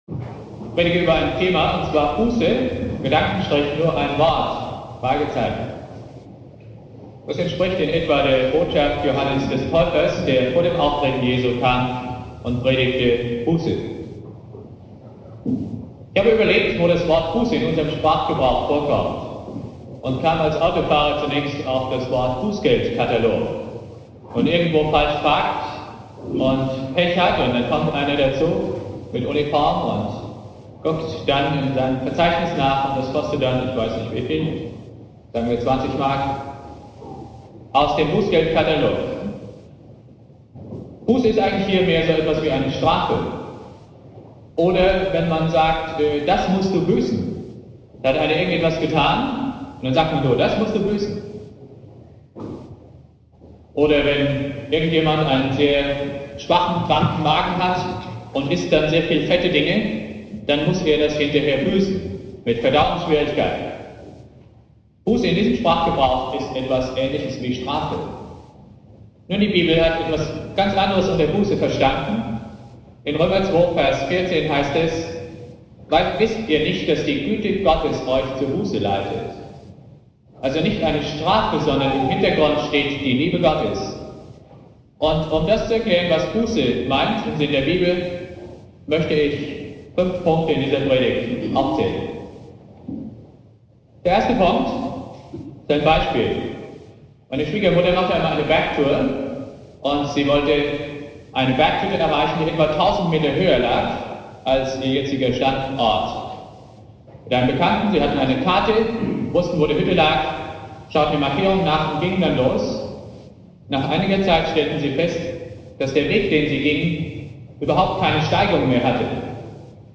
Predigt
4.Advent Prediger